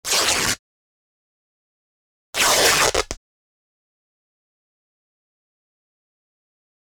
household
Cloth Tears Good Long Rips